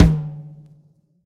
drum-hitclap.ogg